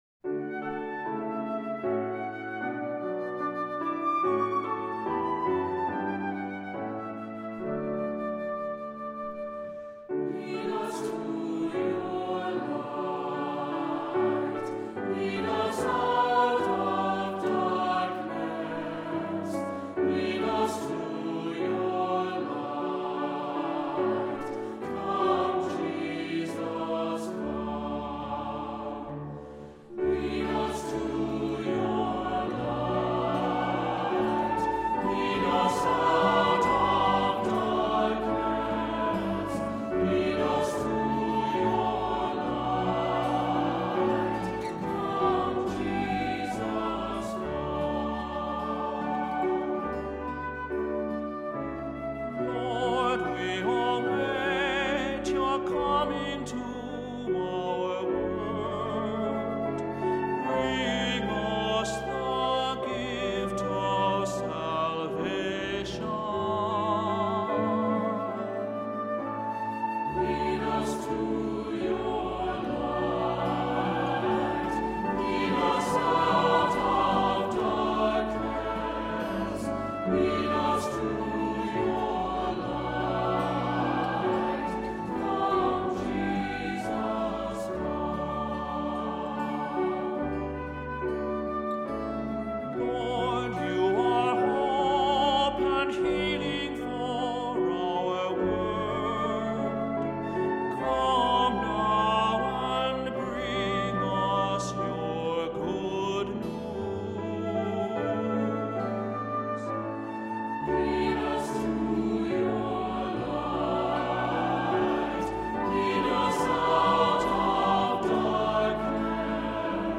Voicing: SATB; Cantor; Priest; Assembly